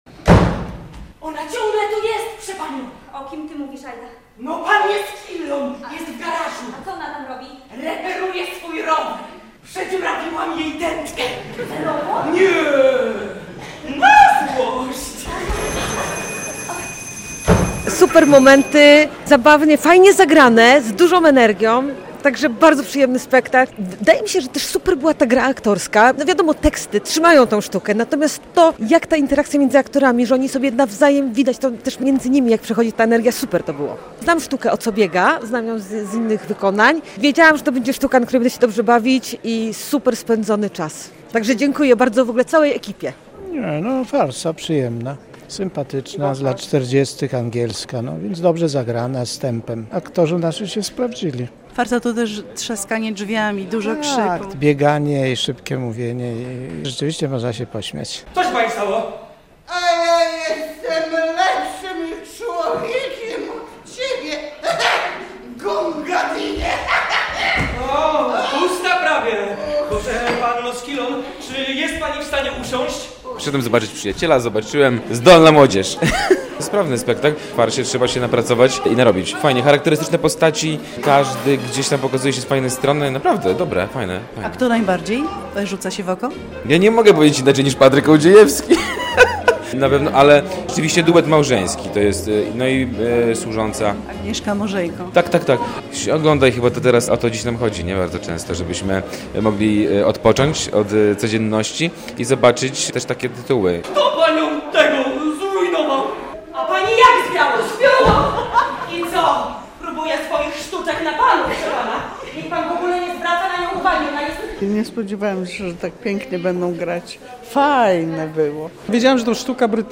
Teatr Dramatyczny inauguruje spektaklem "O co biega?" nowy sezon artystyczny - relacja